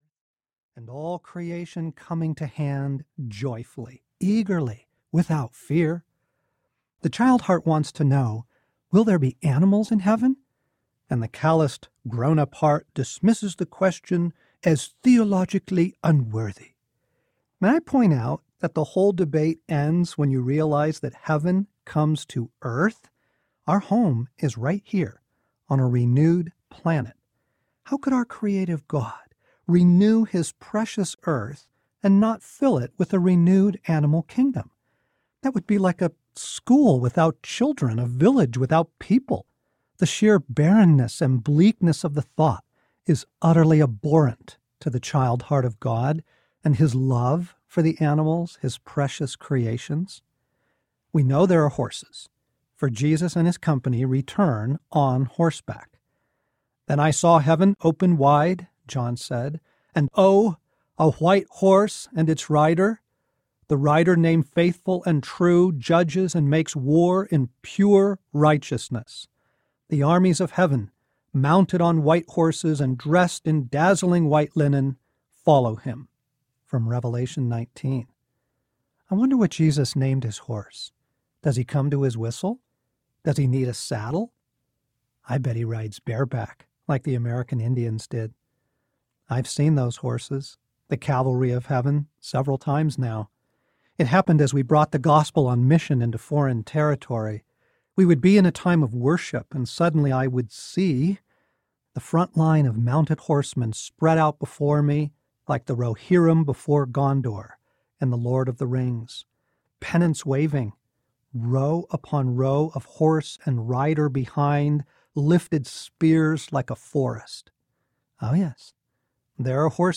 All Things New: Heaven, Earth, and the Restoration of Everything You Love Audiobook